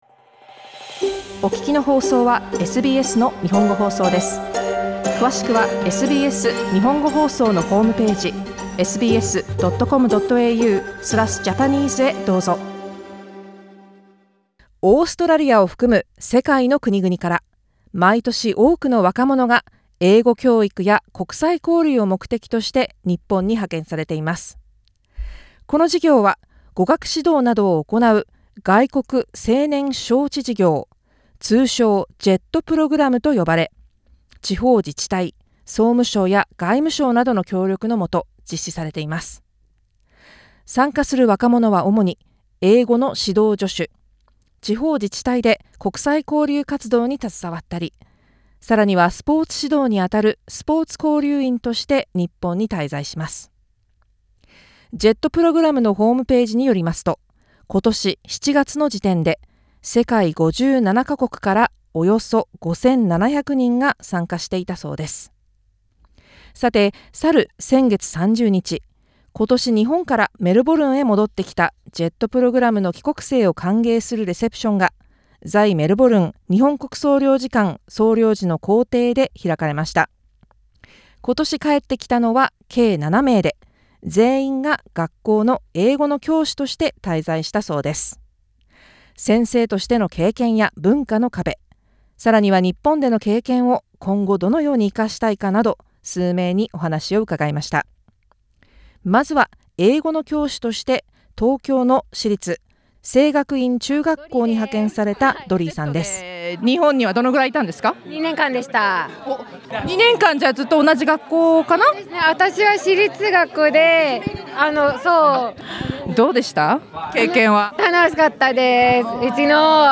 先月30日、今年日本からメルボルンへ戻ってきたJETプログラムの帰国生を歓迎するレセプションが、在メルボルン日本国総領事館総領事の公邸で開かれました。JET帰国生に、日本でのエピソードやこれからの目標など、お話を伺いました。